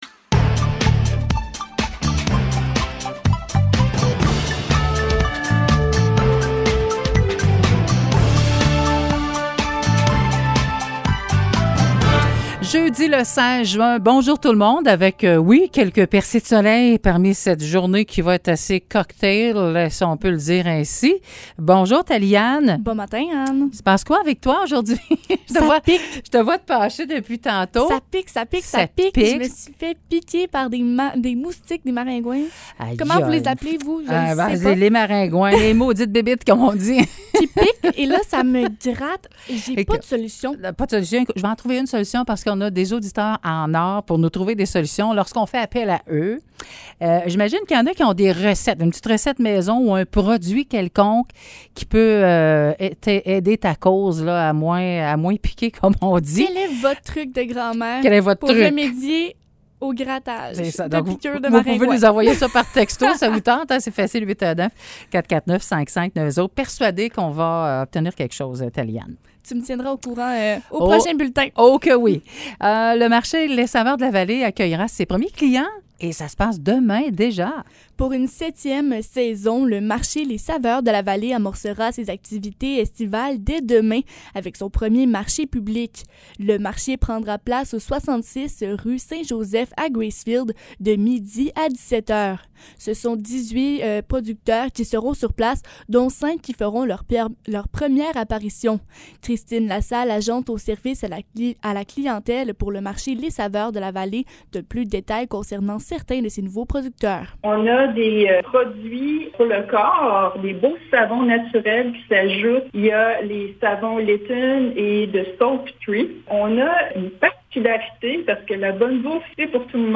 Nouvelles locales - 16 juin 2022 - 9 h